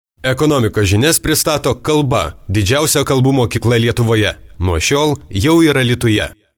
Sprecher litauisch für TV / Rundfunk / Industrie.
Professionell voice over artist from Lithuania.